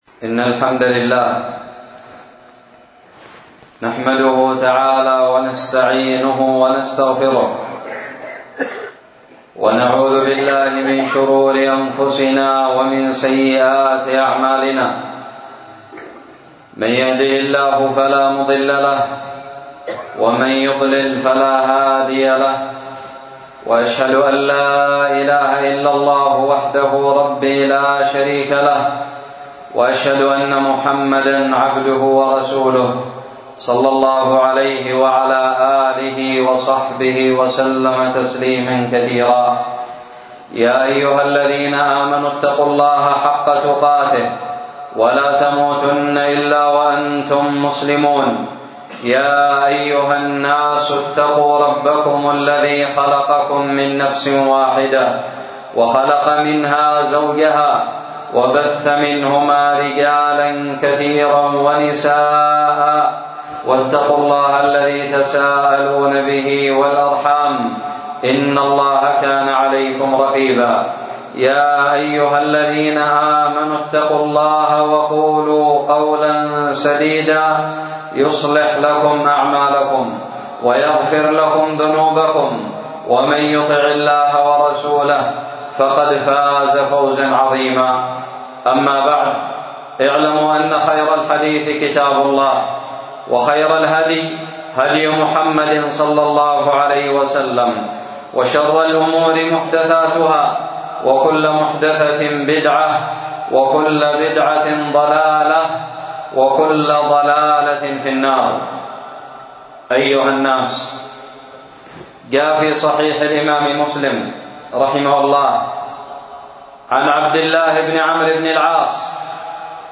خطب الجمعة
ألقيت بدار الحديث السلفية للعلوم الشرعية بالضالع في 29 رجب 1440هــ